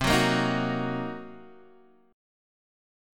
C Minor 7th Flat 5th
Cm7b5 chord {x 3 4 3 4 2} chord